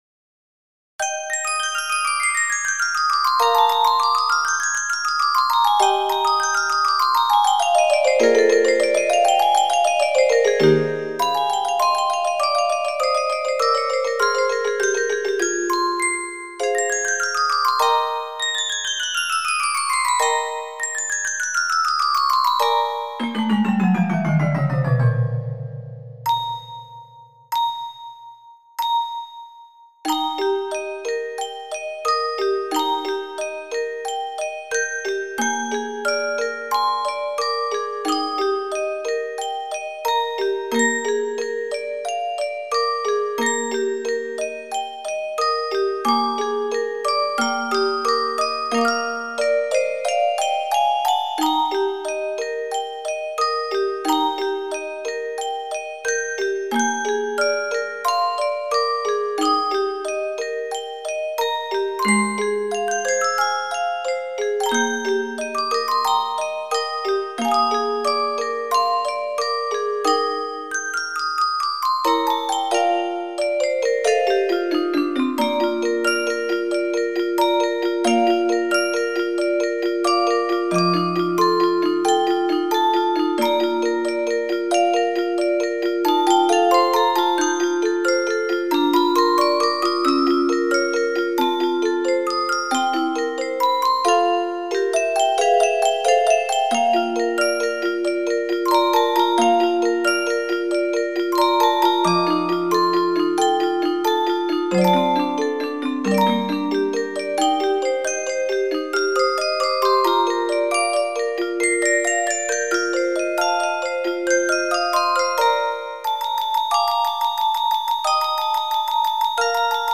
クラシック曲（作曲家別）－MP3オルゴール音楽素材
オルゴール チェレスタ ミュージックボックス